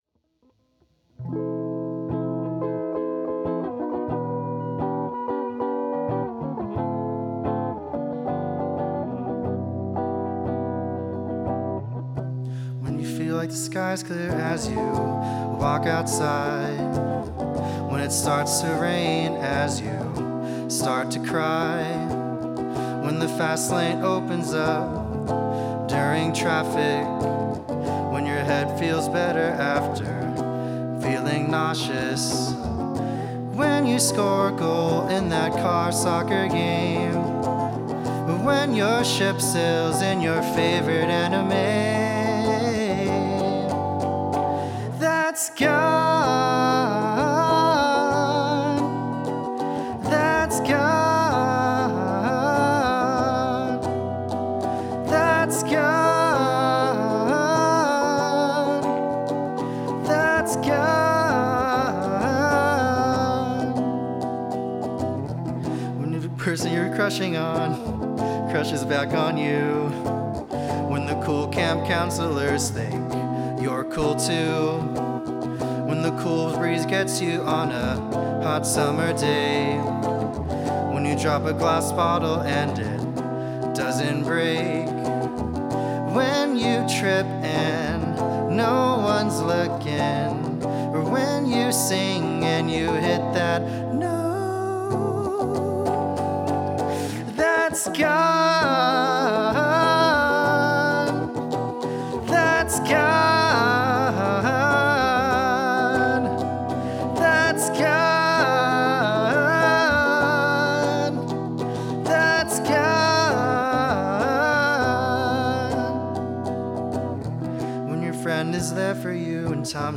Music form Summer Camp
Offertory